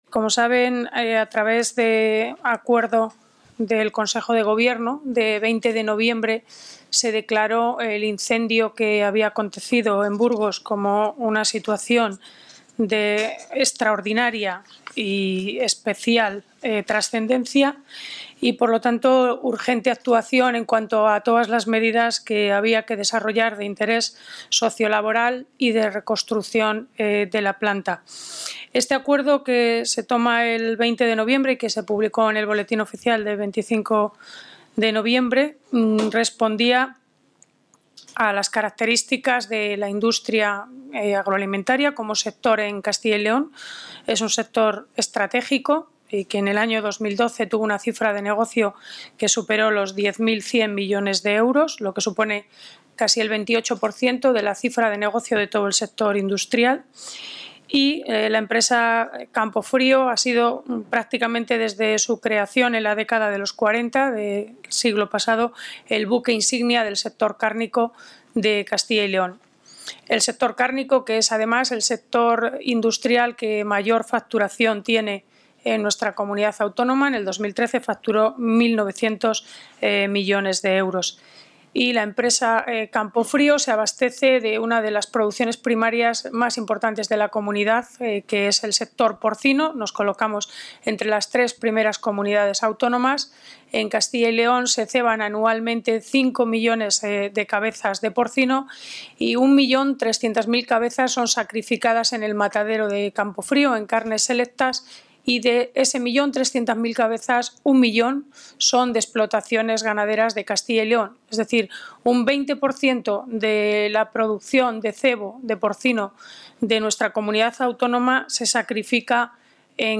La consejera de Agricultura y Ganadería, Silvia Clemente, preside la reunión de la Comisión Delegada para coordinar las medidas para paliar los daños producidos por la destrucción de la factoría La Bureba de Campofrío, en Burgos. Se adjunta material gráfico del encuentro y audio con declaraciones de la consejera de Agricultura y Ganadería.